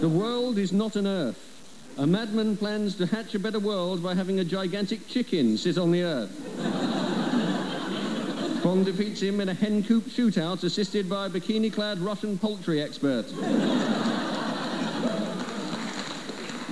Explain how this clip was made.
The Now Show is a BBC Radio 4 comedy sketch show which saves money by inviting the studio audience to write the jokes just before the show starts. One of my answers was read out on air.